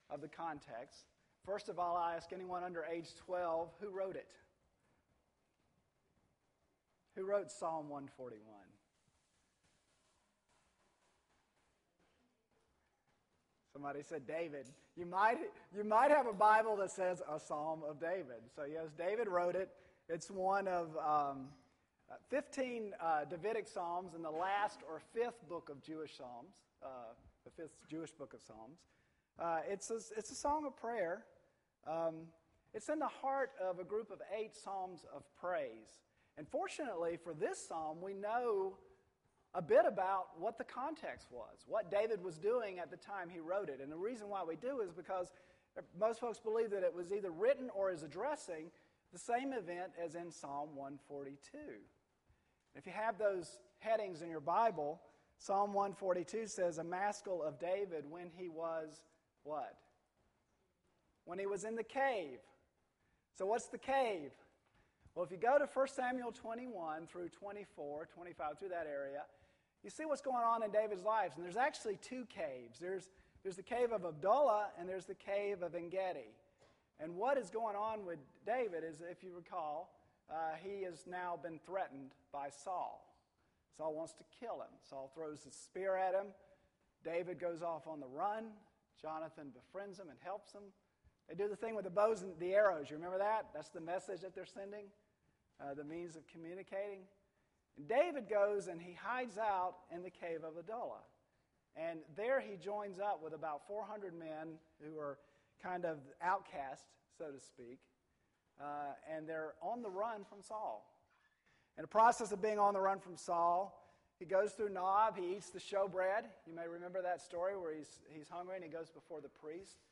Play the sermon Download Audio ( 22.84 MB ) Email Sanctification and Safety Under Stress Details Series: General Topics Date: 2010-09-12 Scripture : Psalm 141